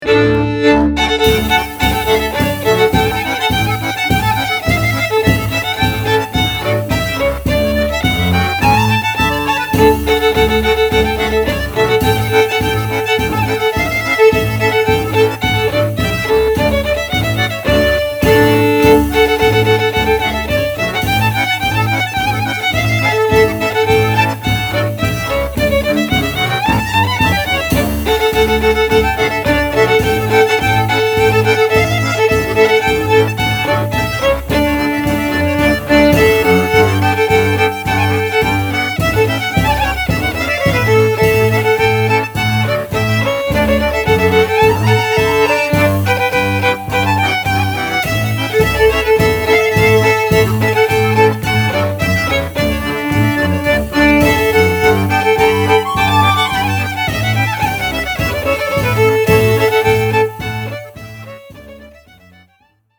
Recorded at Bay Records, Berkeley, CA, May 2003
Genre: Klezmer.
(Jewish) violin, accordion, bass, baraban (32)   2:13